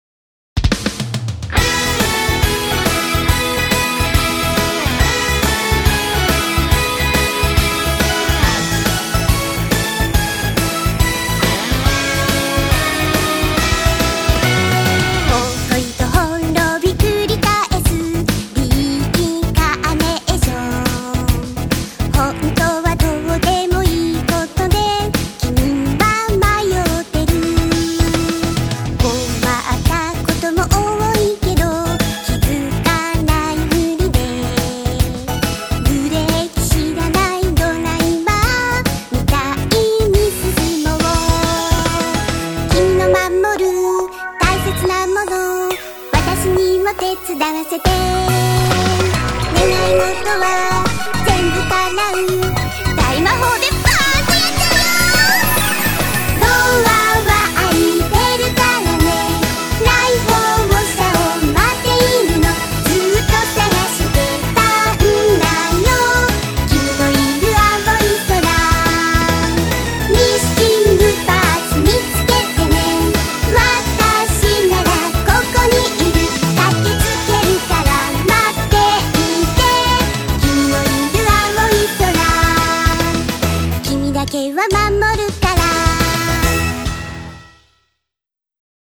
主題歌